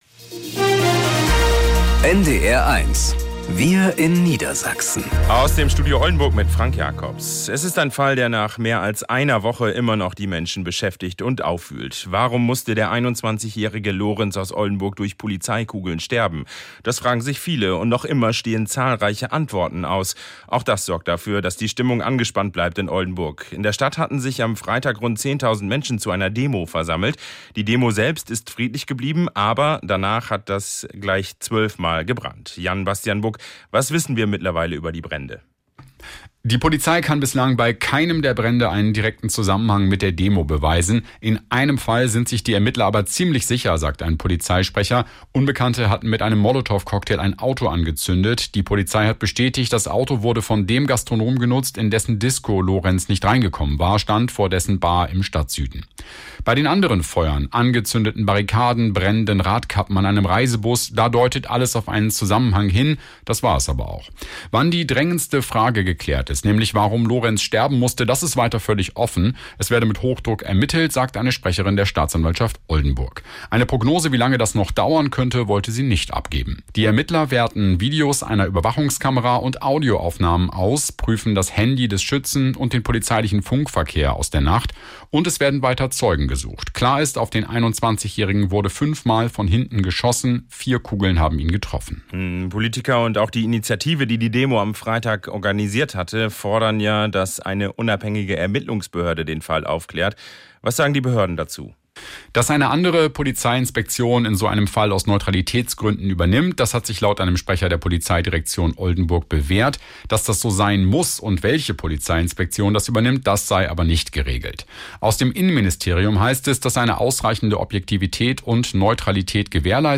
… continue reading 4 حلقات # Tägliche Nachrichten # Nachrichten # NDR 1 Niedersachsen